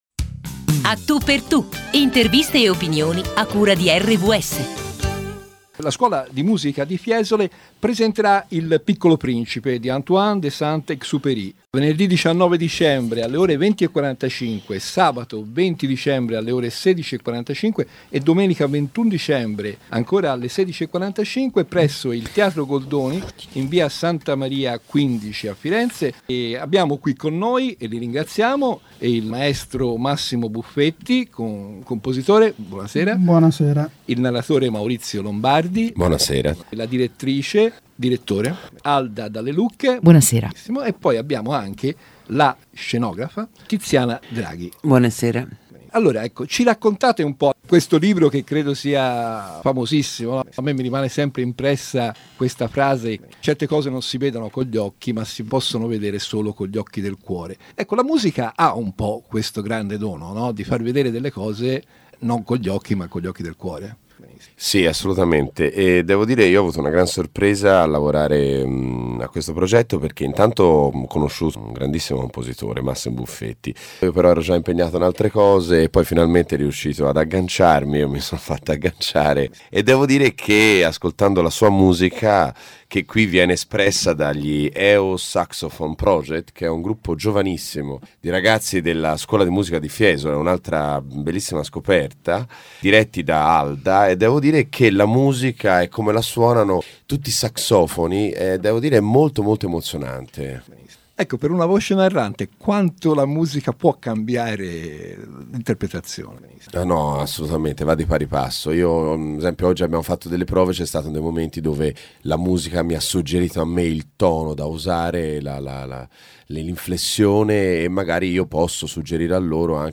Eos Saxophone Project, un gruppo di giovani musicisti, e La Scuola di Musica di Fiesole presentano in tre date fiorentine “Il piccolo principe” di Antoine de Saint-Exupery.